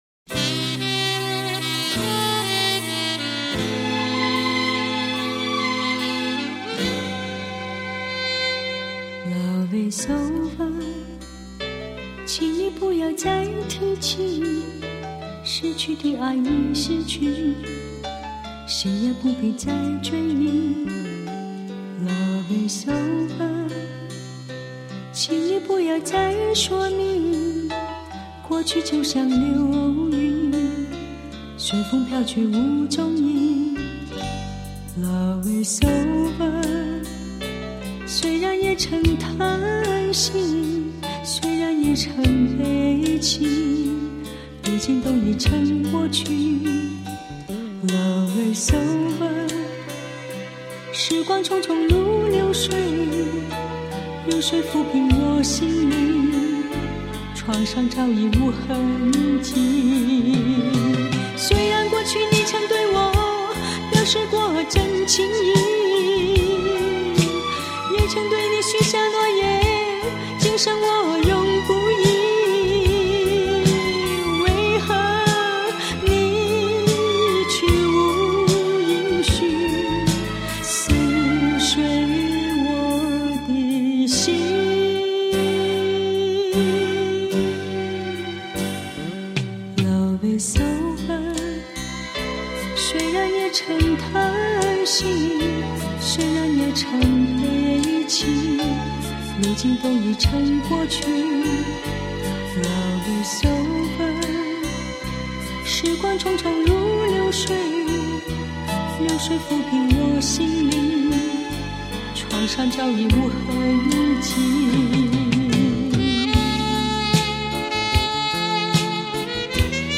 本碟采用24 bit processing 数码技术重新编制
重拾保留隐藏在原录音带内之方位讯号
大大提高了音场透明度及层次感
另音色发挥得更完美